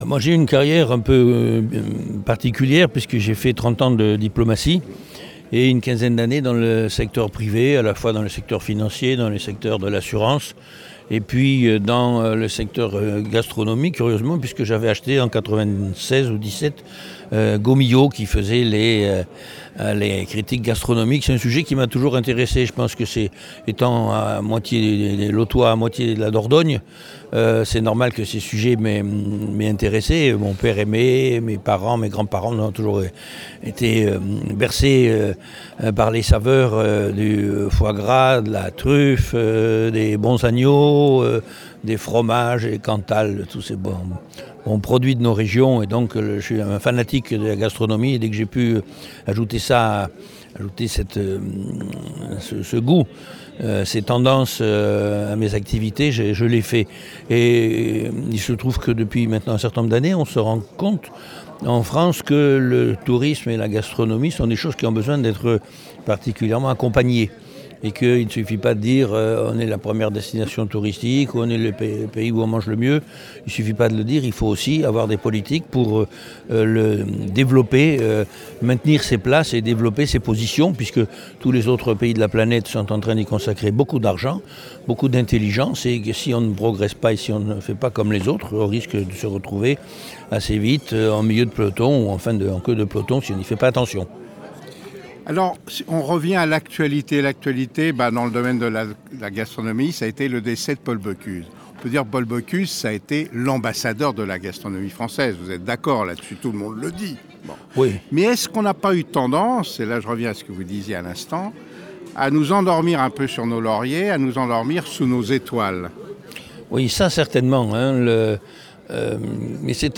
Philippe Faure explique son parcours de la  diplomatie gourmande à la tête d’ATOUT FRANCE…